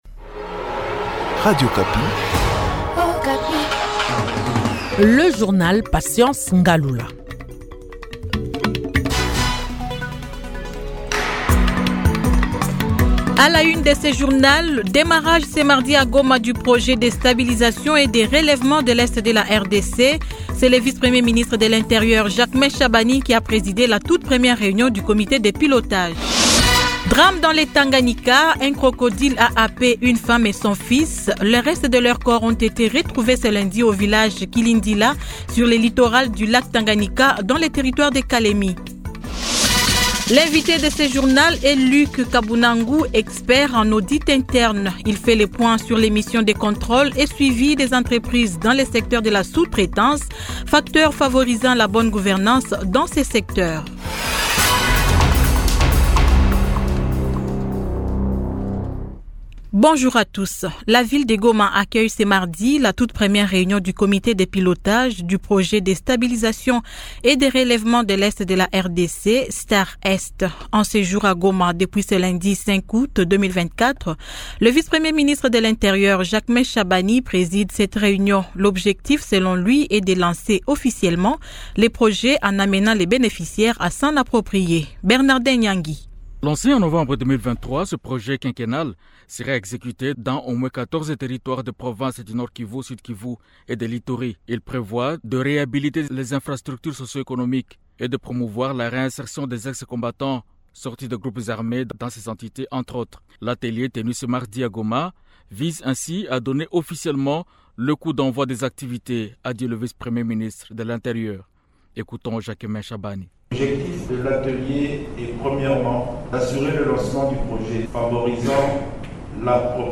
Journal 15H00